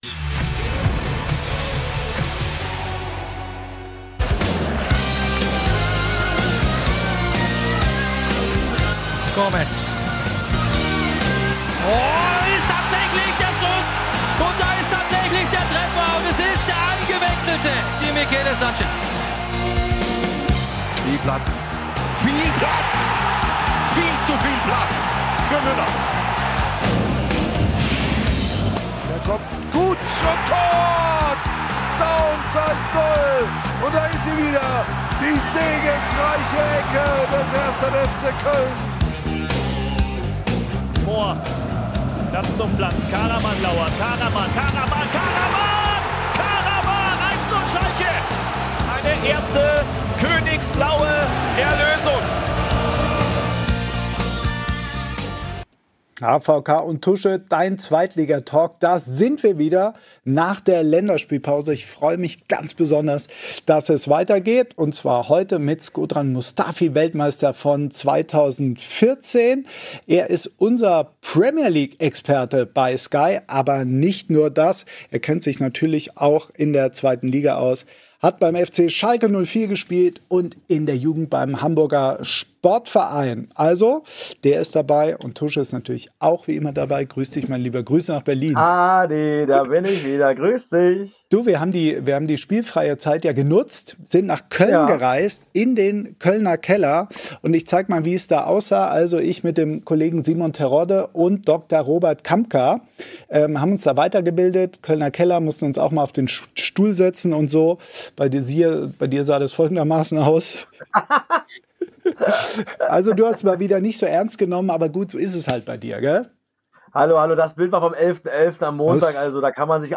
Weltmeister Shkodran Mustafi war im Fußballinternat beim Hamburger SV und erlebte einen bitteren Abstieg mit Schalke 04. Der Verteidiger spricht über seinen WG-Kumpel in Brasilien, den Titel Weltmeister und natürlich seine Ex-Klubs. Dein Sky Talk über die 2. Bundesliga.